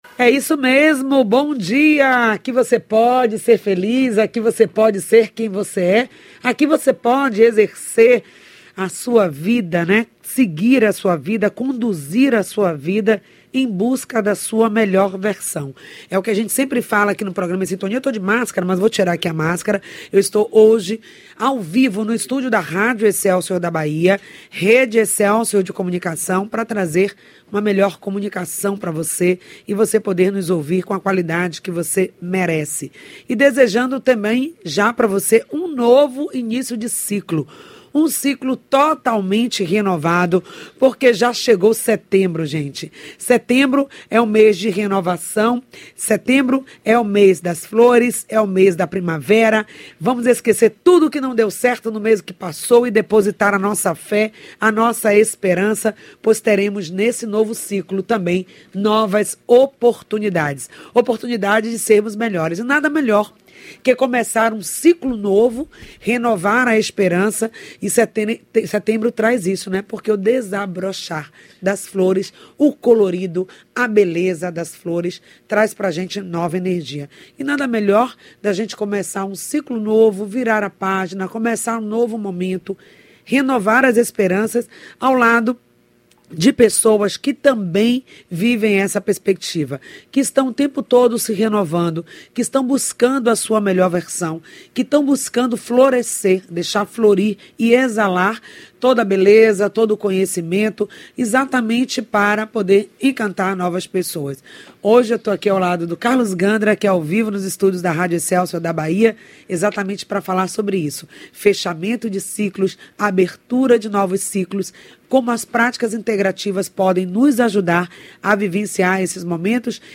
A entrevista evidencia também como as PICs – Prática Integrativas e Complementares, assim como boa alimentação, suplementação e Atividades físicas podem contribuir com o tratament e para a saúde integral.